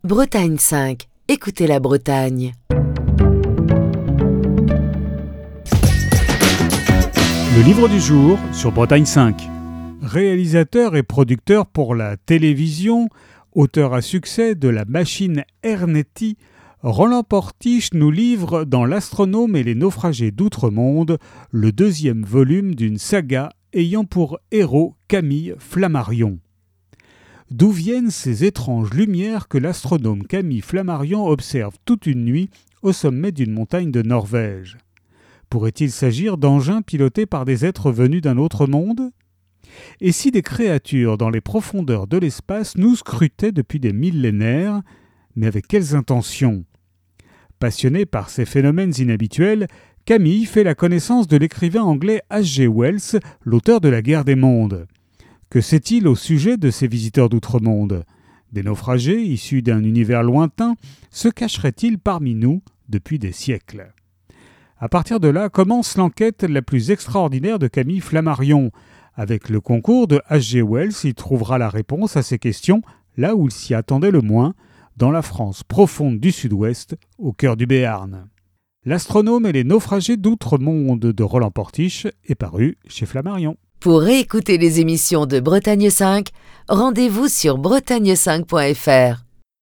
Chronique du 1er juillet 2025.